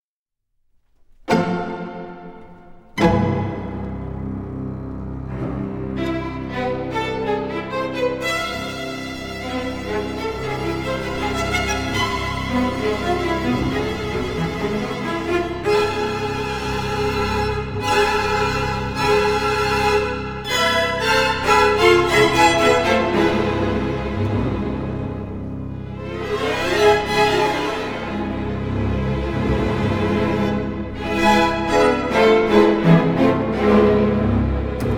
Жанр: Классика